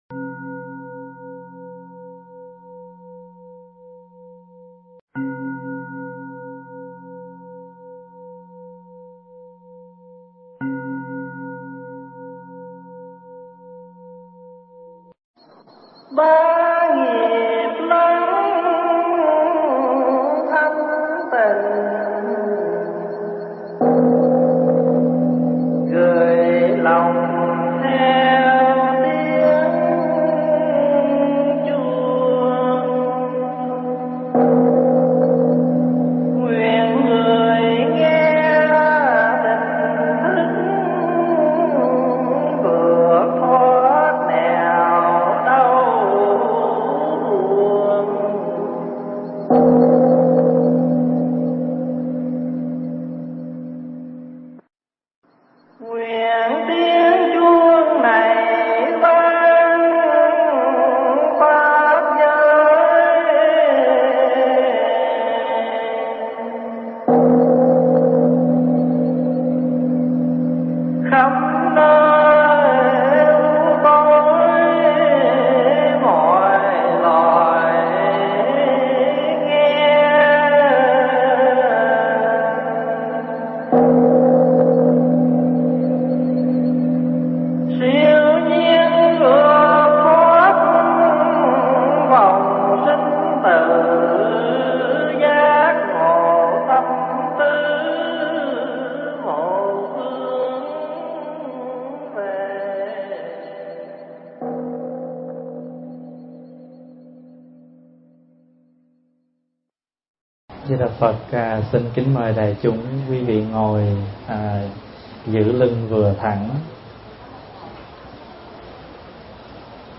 Tải mp3 Thuyết Giảng Xuất Gia Tại Gia